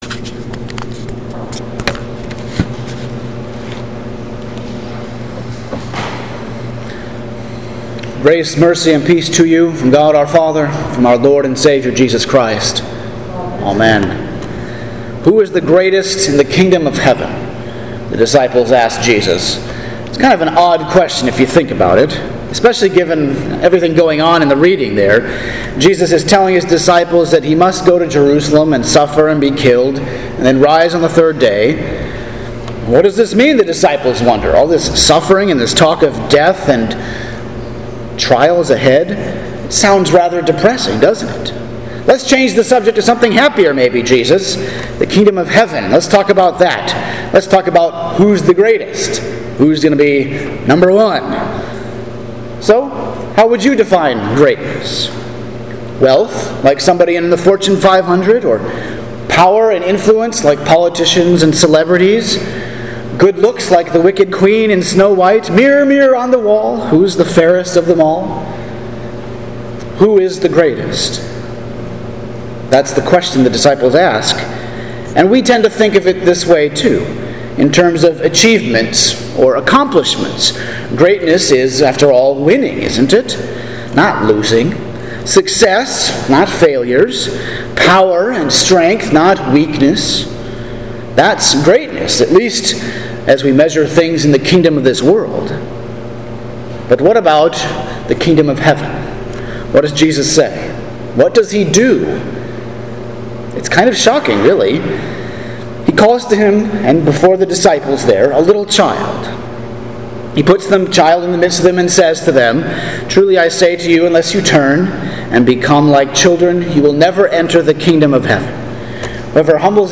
Sermon for Pentecost 14 – September 6, 2020